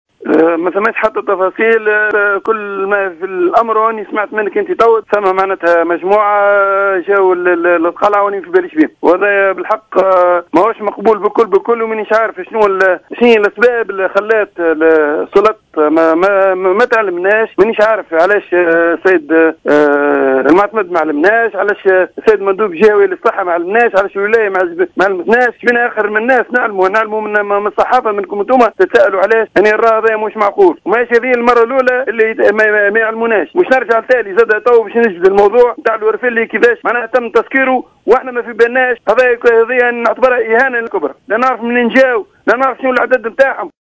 و عبّر الورجيني في تصريح للجوهرة أف أم، عن استغرابه من عدم إعلام المصالح البلدية بقدوم الخاضعين للحجر الصحي الإجباري، لا من قبل الوالية، أو معتمد الجهة أو المدير الجهوي للصحة، رغم أهمية الموضوع.